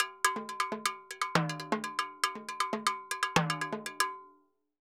Timbaleta_Salsa 120_2.wav